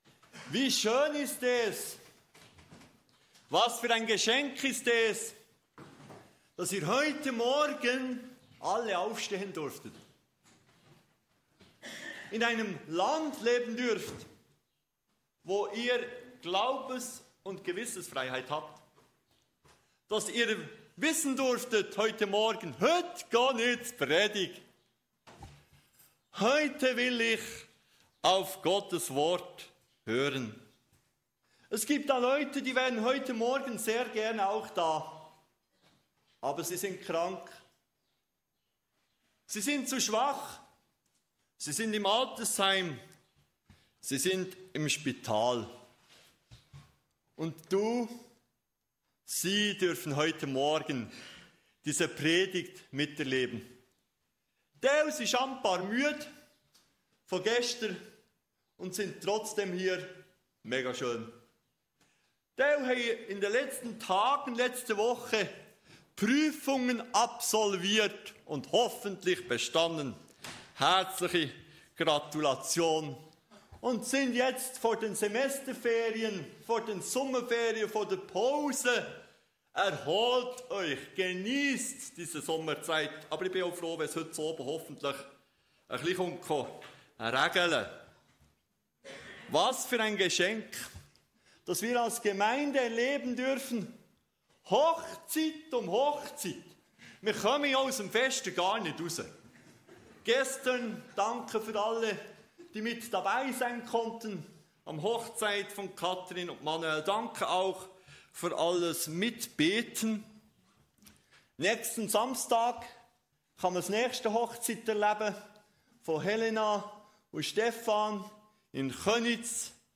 Ein Appell zur Treue, zur Beziehung mit Jesus und zur Hoffnung auf die ewige Herrlichkeit. Bibelstelle: Psalm 73, 23-24 Prediger
Kategorie: Gottesdienst